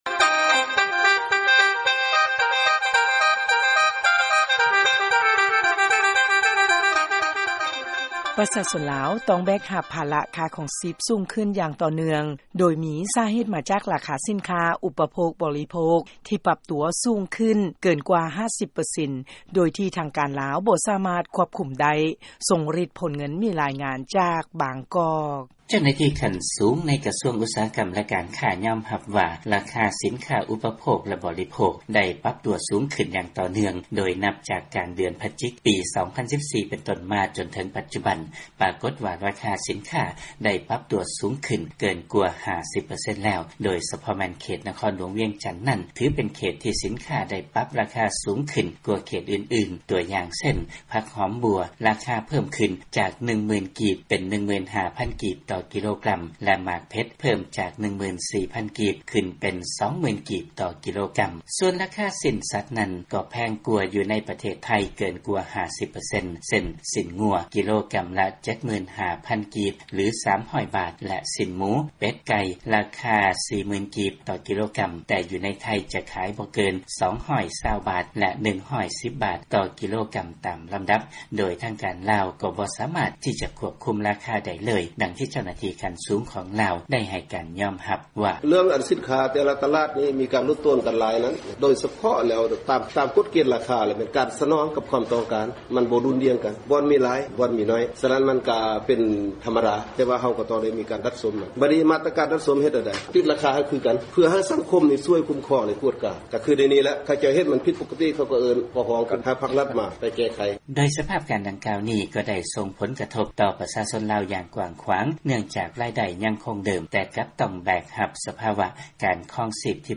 ຟັງລາຍງານ ລາຄາສິນຄ້າ ປັບຕົວສູງຂຶ້ນເກີນກວ່າ 50 ເປີເຊັນ ແຕ່ທາງການລາວ ບໍ່ສາມາດຄວບຄຸມໄດ້.